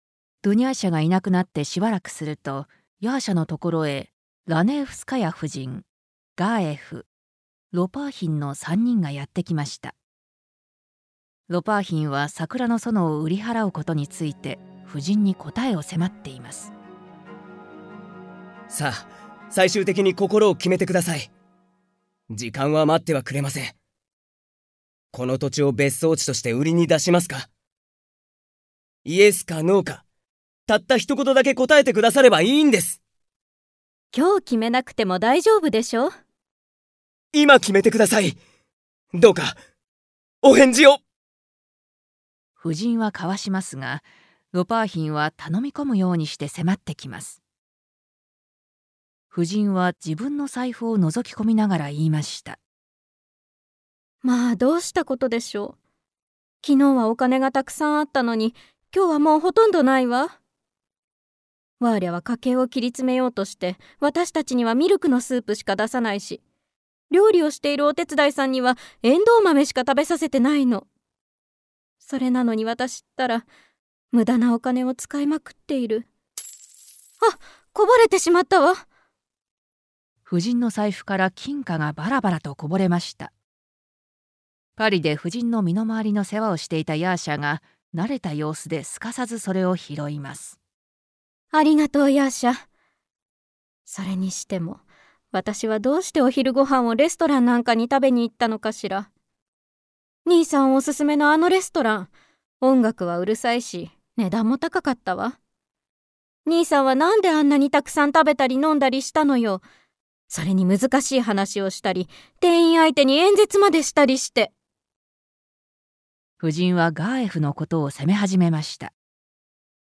[オーディオブック] 桜の園（こどものための聴く名作 40）